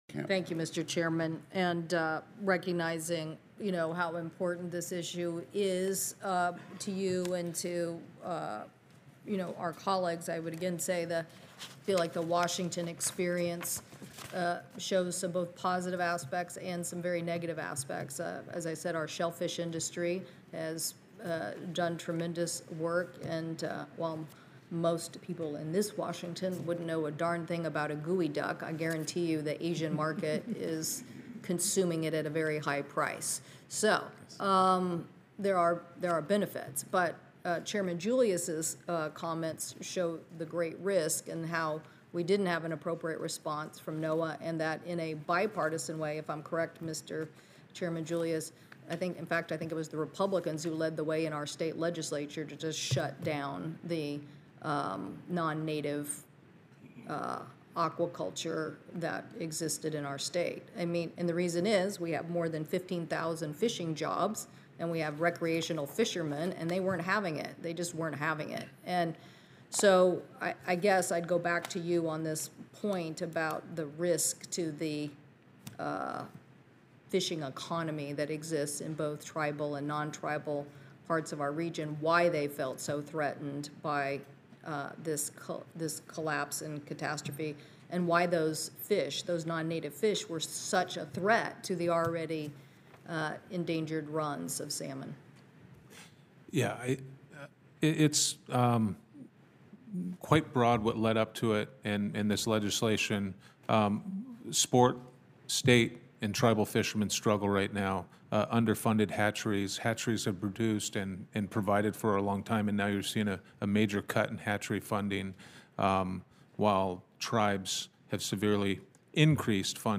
WASHINGTON, D.C. – As the Senate Committee on Commerce, Science, and Transportation prepares to consider legislation to encourage more aquaculture fish farming in American waters, U.S. Senator Maria Cantwell (D-WA), the Ranking Member of the committee, raised concerns about the risks of offshore finfish farming in a hearing this morning.
Video from Senator Cantwell’s witness Q&A is available HERE, and audio is available